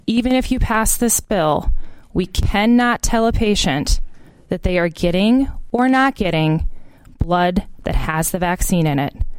A South Dakota House Committee spent hours of debate this morning on whether the state should require blood donors to disclose if they’ve received the vaccine. Testimony revealed there are no tests that could guarantee donated blood is free from the vaccine, a point driven home by Representative Taylor Rehfeld of Sioux Falls before the committee voted.